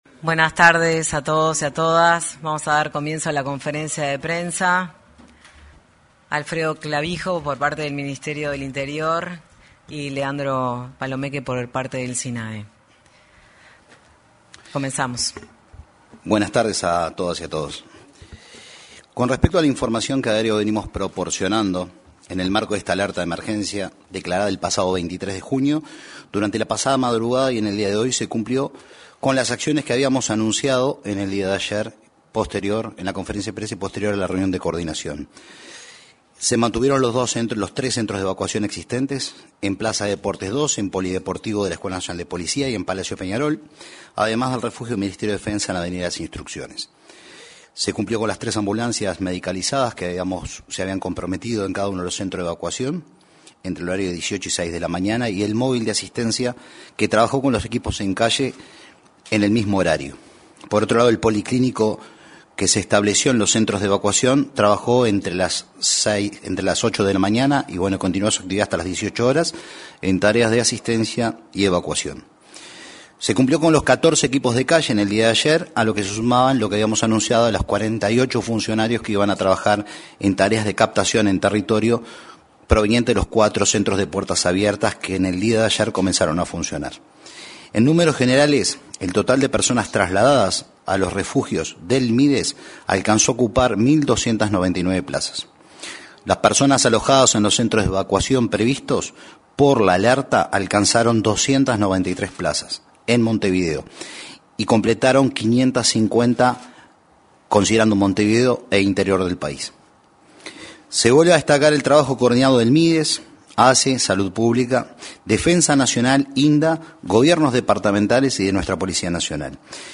Conferencia de prensa del Sistema Nacional de Emergencias
Conferencia de prensa del Sistema Nacional de Emergencias 26/06/2025 Compartir Facebook X Copiar enlace WhatsApp LinkedIn El director del Sistema Nacional de Emergencias (Sinae), Leandro Palomeque, y el subdirector de la Policía Nacional, Alfredo Clavijo, informaron, en una conferencia de prensa en la Torre Ejecutiva, sobre las acciones para proteger a personas en situación de calle ante las bajas temperaturas.